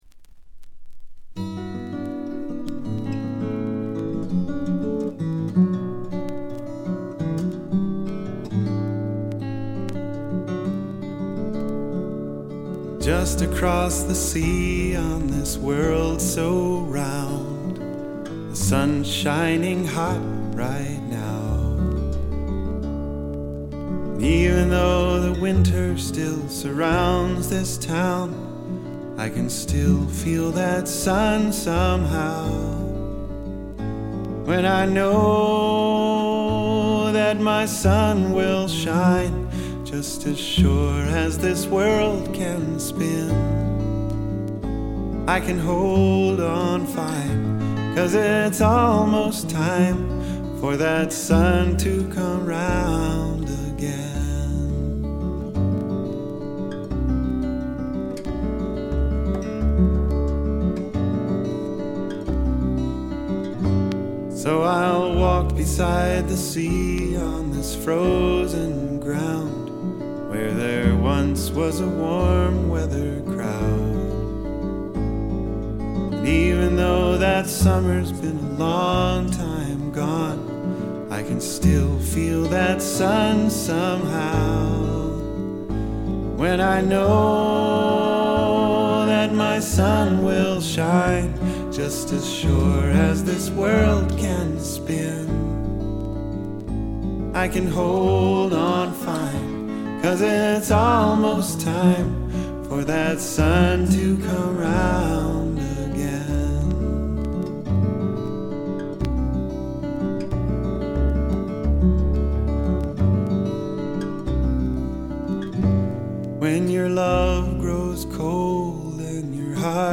試聴曲は現品からの取り込み音源です。
※B1-B2連続です。曲間のノイズもご確認ください。
Guitar, Vocals